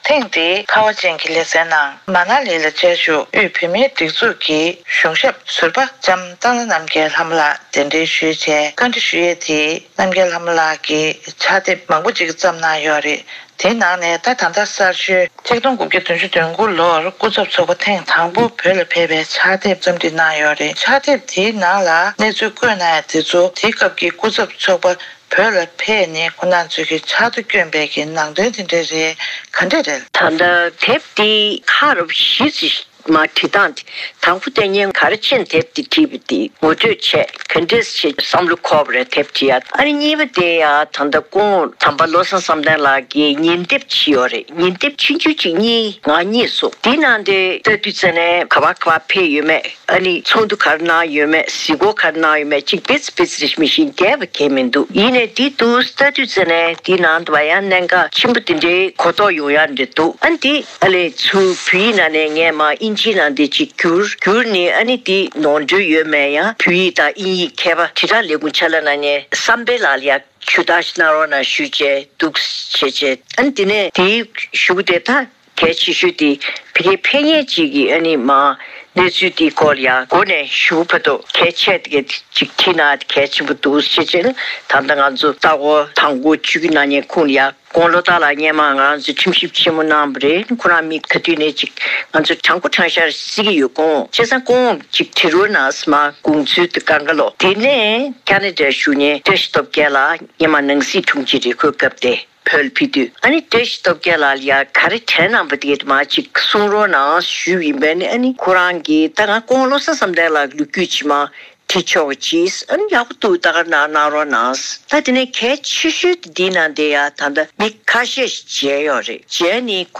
གནས་འདྲི་ཞུས་པ་གསན་གྱི་རེད།